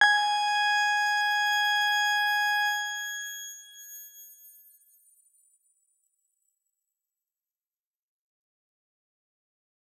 X_Grain-G#5-mf.wav